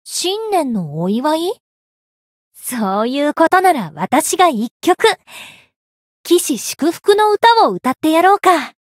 灵魂潮汐-密丝特-春节（摸头语音）.ogg